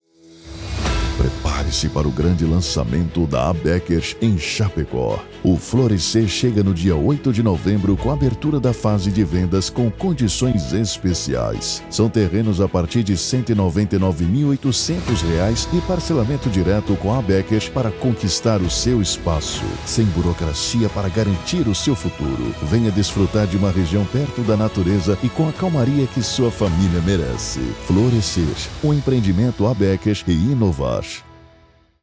SPOT ABECKER FLORECER: